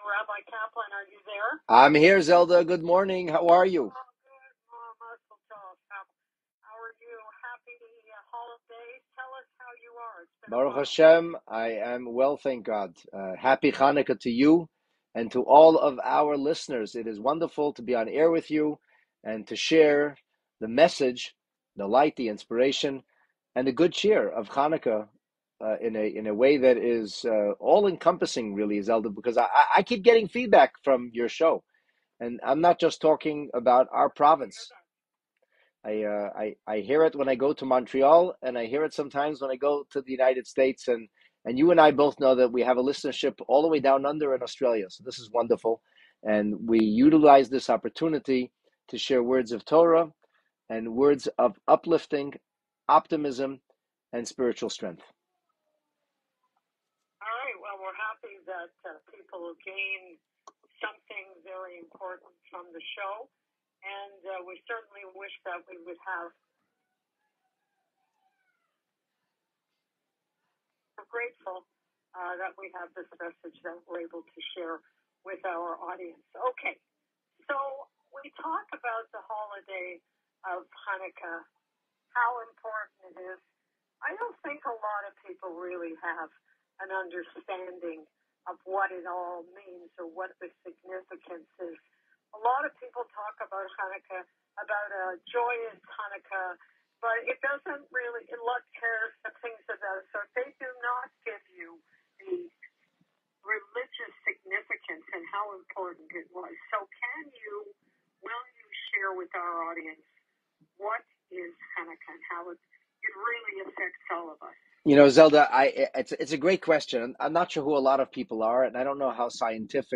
A live radio interview